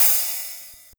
Open Hats
Open Hat (Miss Me).wav